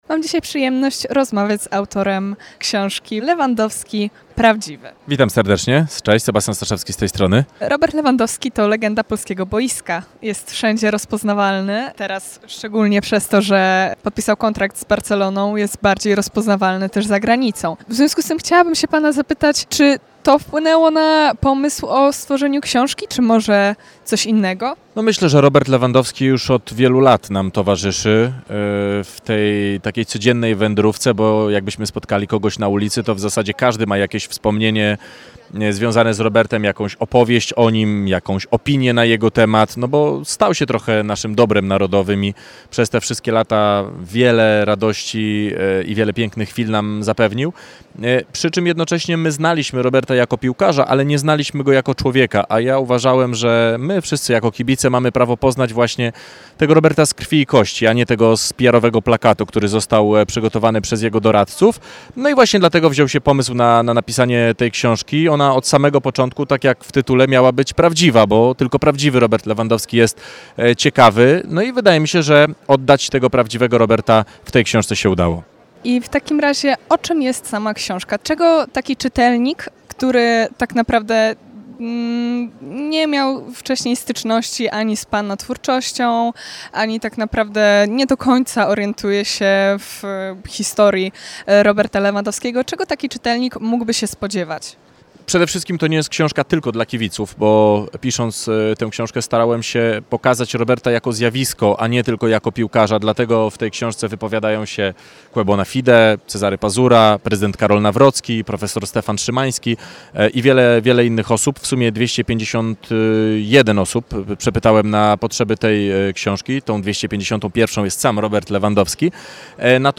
Wrocławskie Targi Dobrych Książek powracają w 33. edycji!